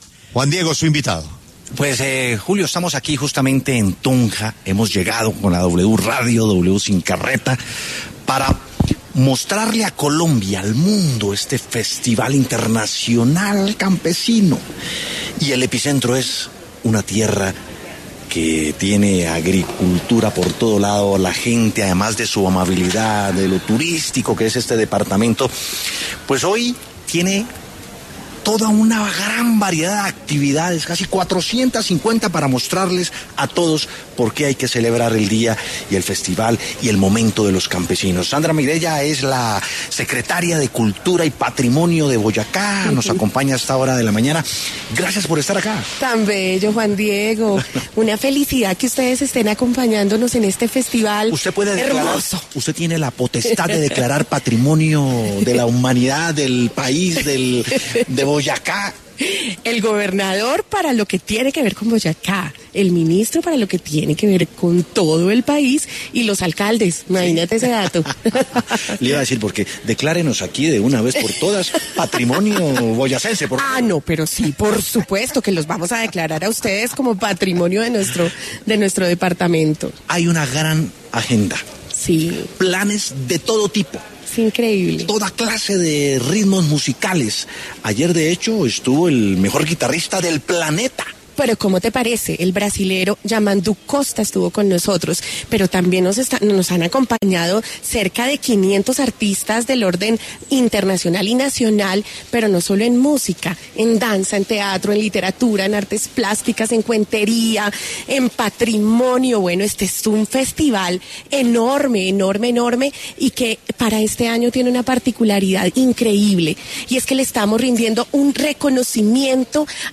Sandra Mireya Becerra, secretaria de Cultura y Patrimonio de Boyacá, conversó con Juan Diego Alvira en La W a propósito del Festival Internacional Campesino 2024 que culminará el domingo 17 de noviembre.